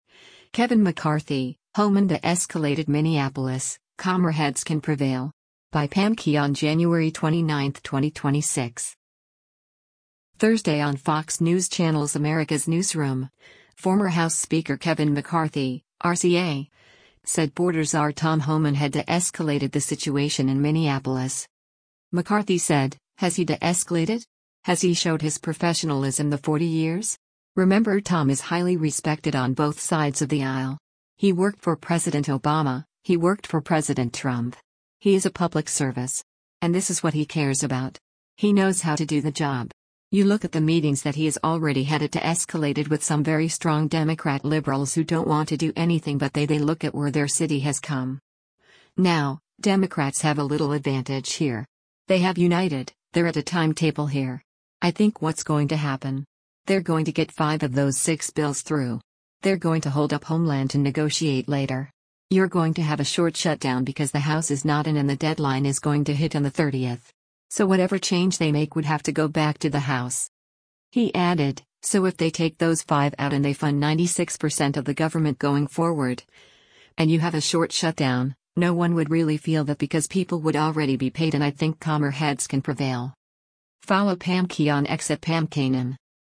Thursday on Fox News Channel’s “Americas Newsroom,” former House Speaker Kevin McCarthy (R-CA) said border czar Tom Homan had de-escalated the situation in Minneapolis.